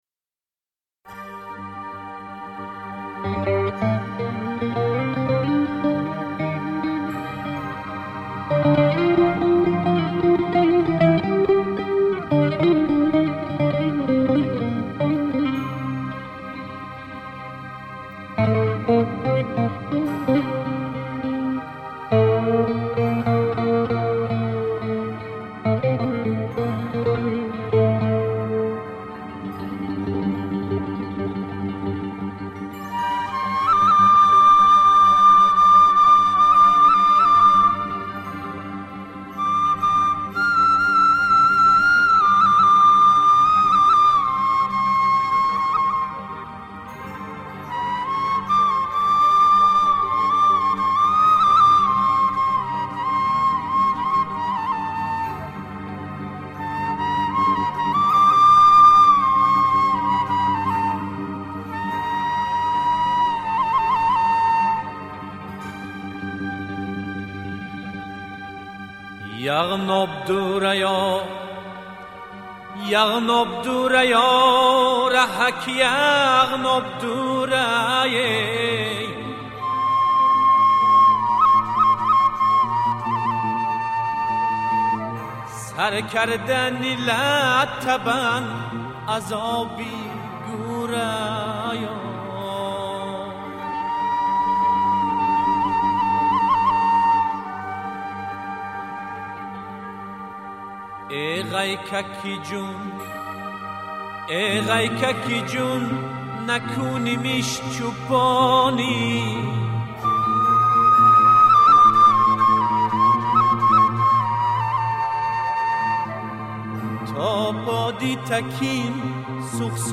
Категория: Халки-Народный